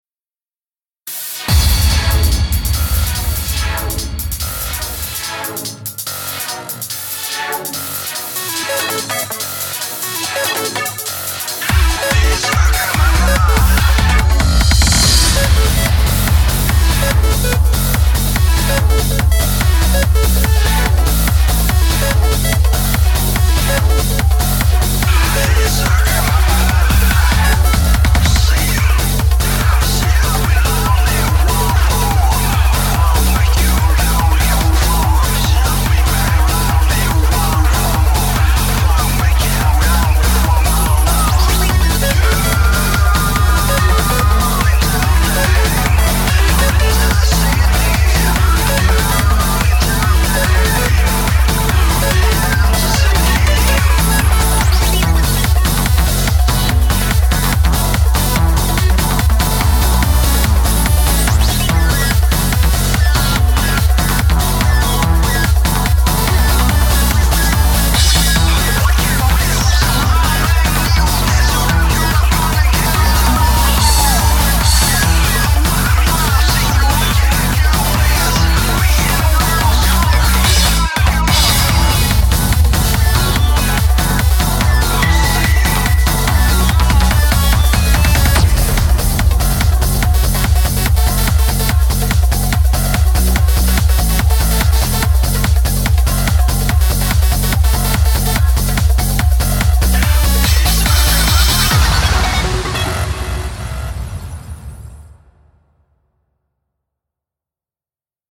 BPM144
Audio QualityPerfect (High Quality)
HARD TRANCE